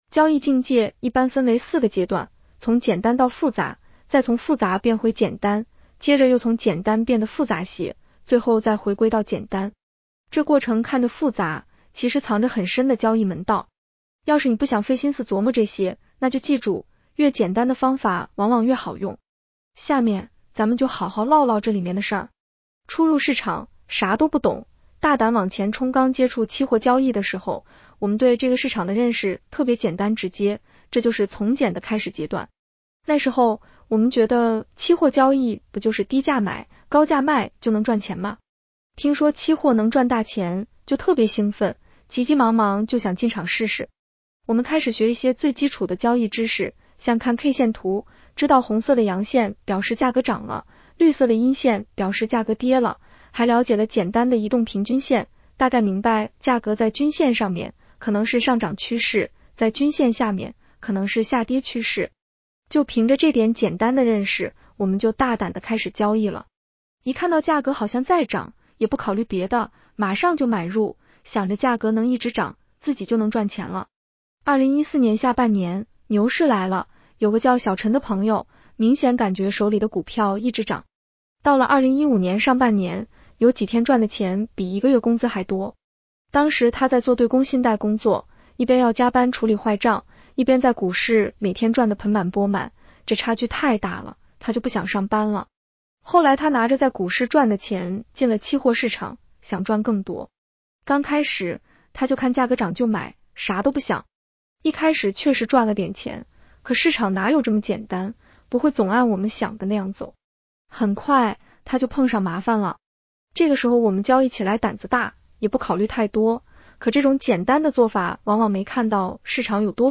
女声普通话版 下载mp3 交易境界一般分为四个阶段：从简单到复杂，再从复杂变回简单，接着又从简单变得复杂些，最后再回归到简单。